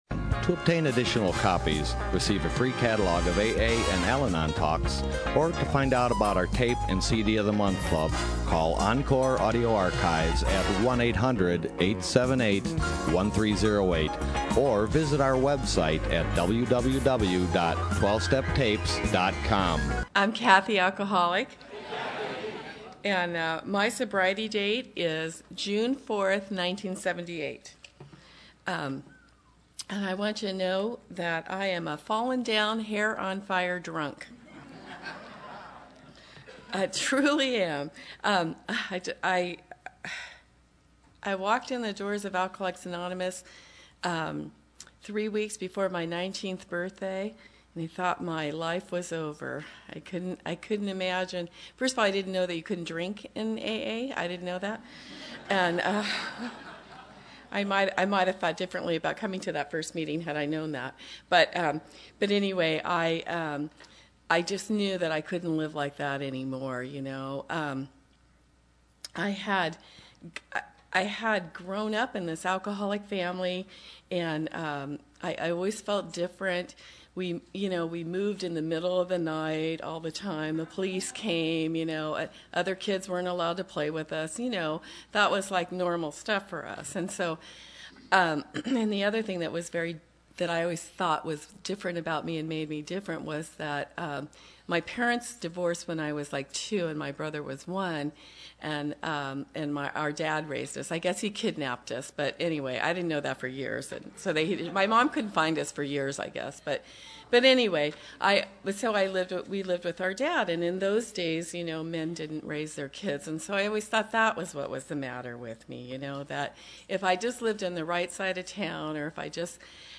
Orange County AA Convention 2014 - Long Timers